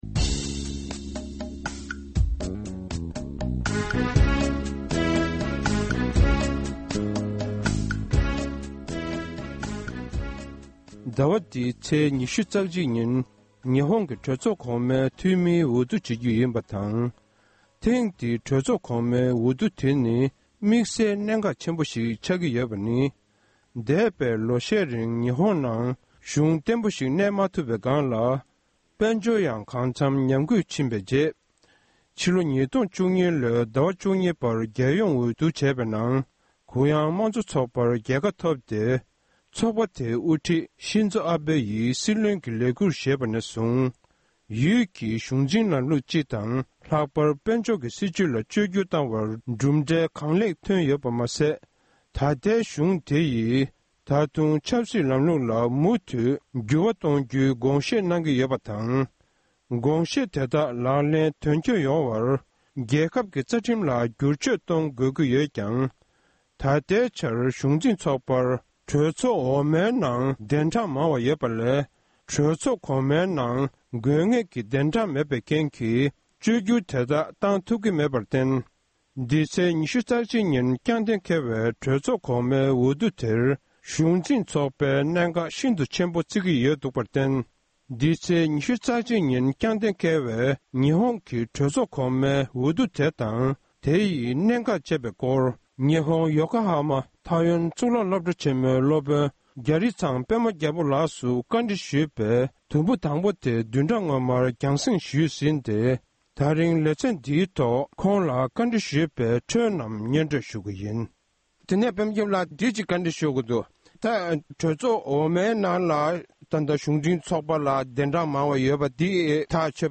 གླེང་མོལ་ཞུས་པར་མུ་མཐུད་གསན་རོགས༎